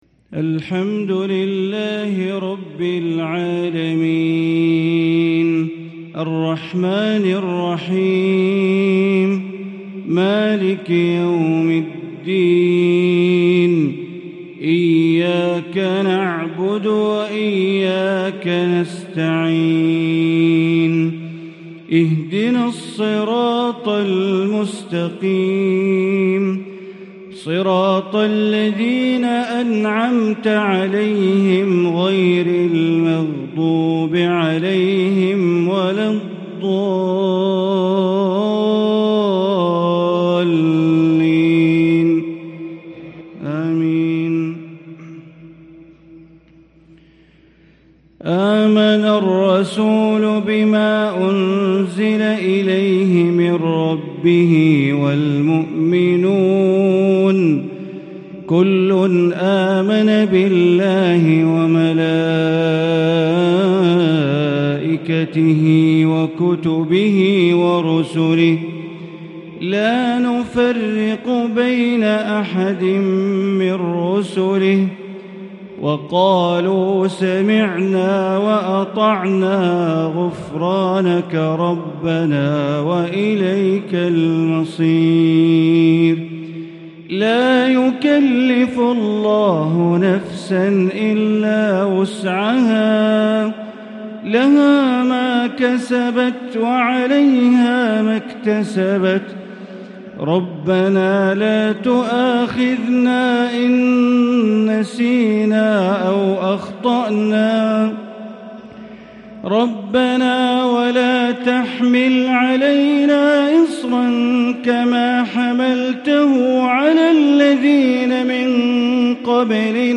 مغرب السبت 7-2-1444هـ من سورتي البقرة و آل عمران | Maghreb prayer from Surah al-Baqarah and Al-Imran 3-9-2022 > 1444 🕋 > الفروض - تلاوات الحرمين